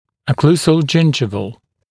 [ə’kluːzəl-ˈdʒɪndʒɪvl] [-səl][э’клу:зэл-ˈджиндживл]окклюзионнодесневой